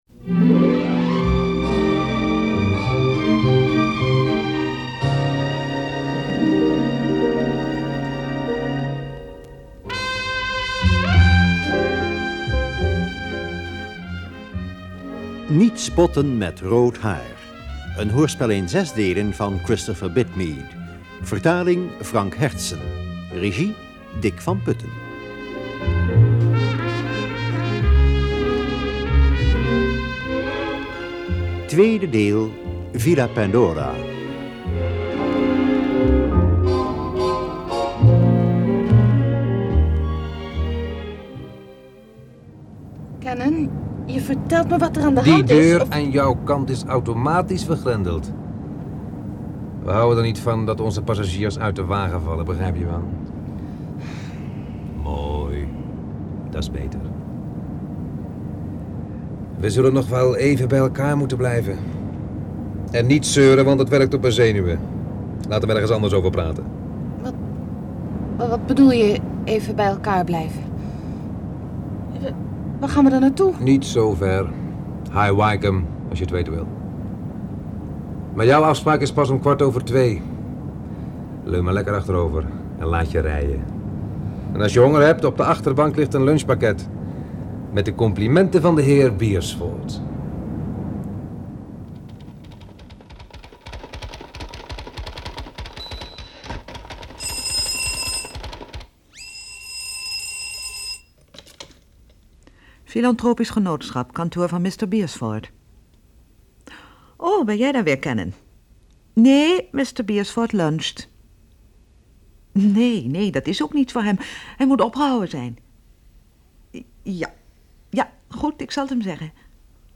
Dit 6-delige hoorspel duurt in totaal ongeveer 3 uur en 36 minuten.